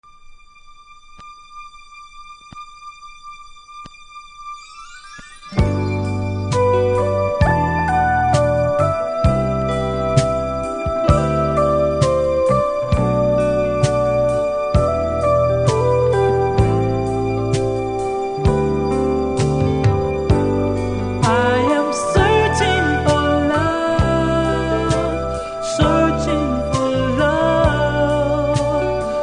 見た目NMですが出だし何発かノイズありますので試聴で確認下さい。